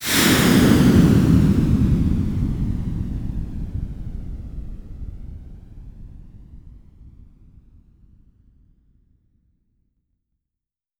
Kesan Bunyi Roket Berlepas.mp3